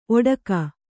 ♪ oḍaka